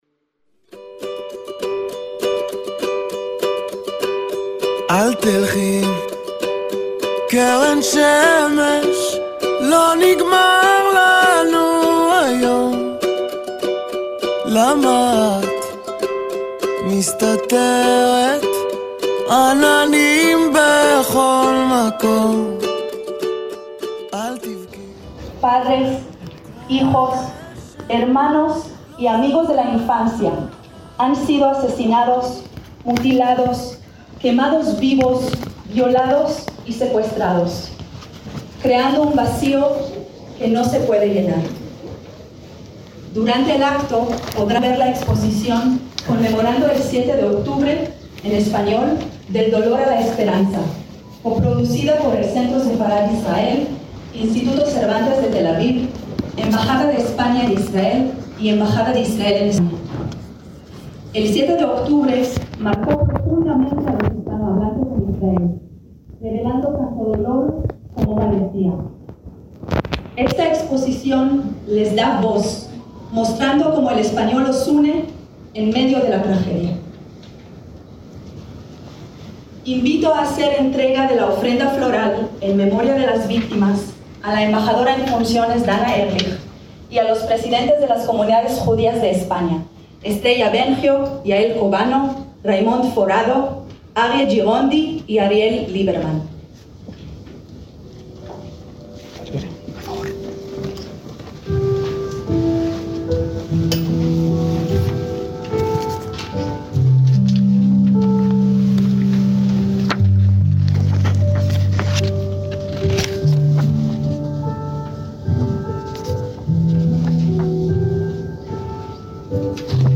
ACTOS EN DIRECTO - El 23 de octubre de 2025 tuvo lugar en Madrid un acto con motivo del segundo aniversario del 7 de octubre de 2023 que desató la guerra de Israel contra Hamás.